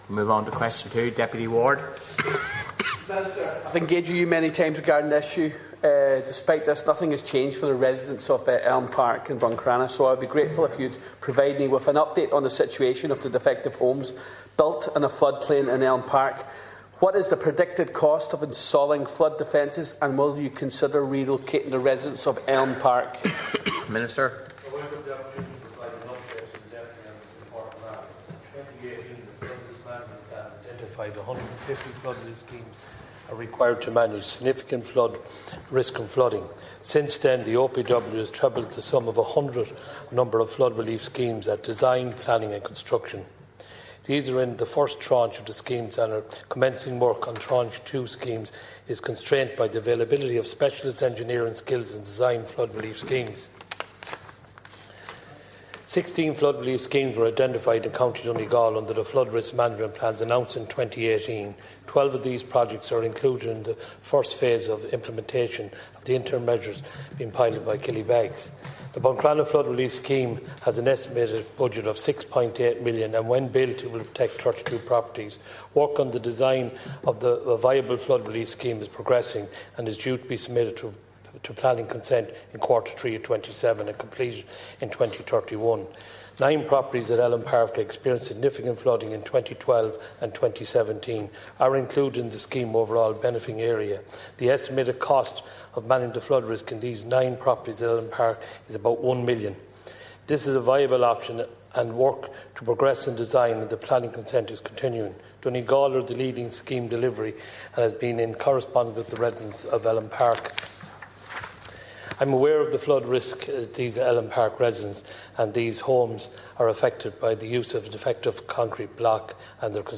The Minister of State in the department, Kevin Boxer Moran, says he and Minister Charlie McConalogue are actively working to find a solution: